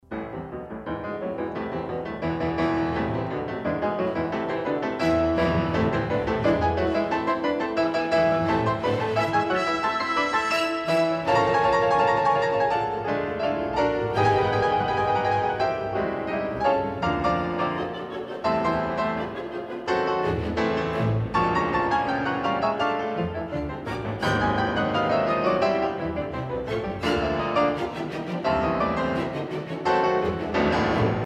原速度，钢琴断奏主题